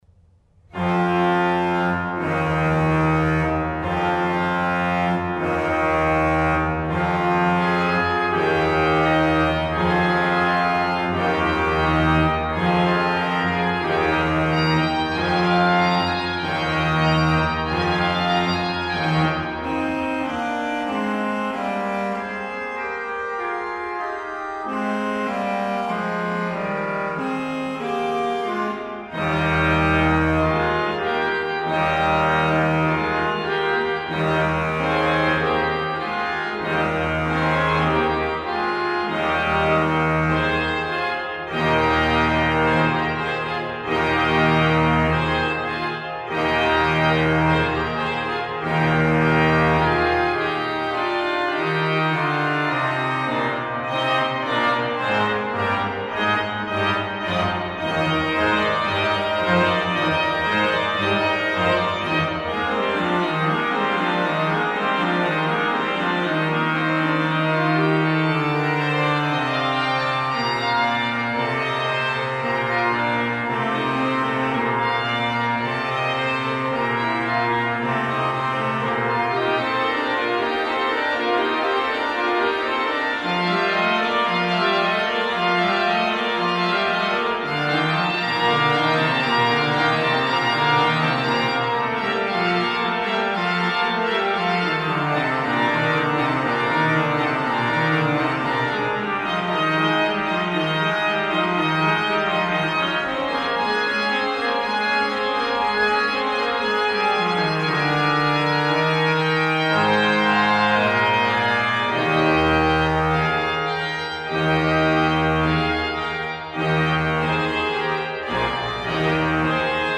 French organ music of the Baroque era.